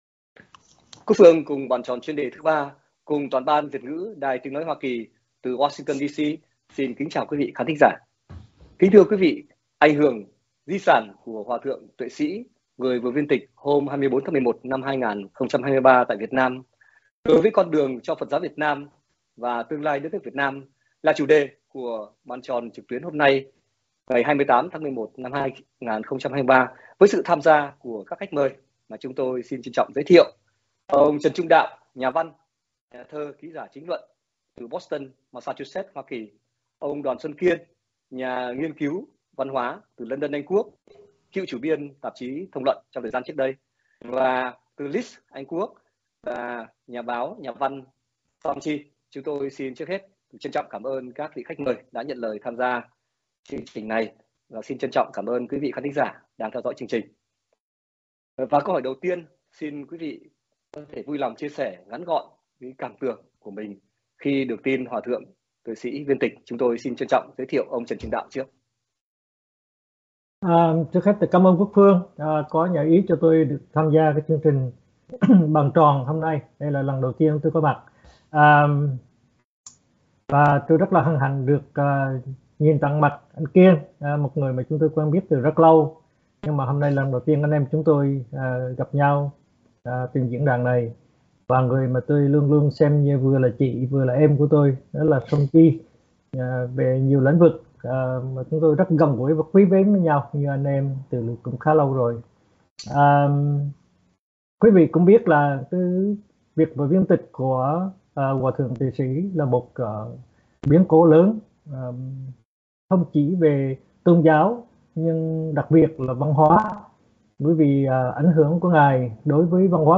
Bàn Tròn Chuyên Đề Thứ Ba của VOA Tiếng Việt từ Hoa Kỳ cùng các khách mời thảo luận ảnh hưởng, di sản của Hòa thượng Tuệ Sỹ, vị cao tăng của Giáo Hội Phật Giáo Việt Nam thống nhất và con đường tiếp nối của Phật Giáo Việt Nam nói riêng, tương lai của đất nước nói chung